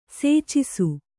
♪ sēcisu